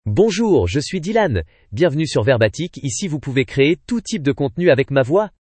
Dylan — Male French (France) AI Voice | TTS, Voice Cloning & Video | Verbatik AI
Dylan is a male AI voice for French (France).
Voice sample
Listen to Dylan's male French voice.
Dylan delivers clear pronunciation with authentic France French intonation, making your content sound professionally produced.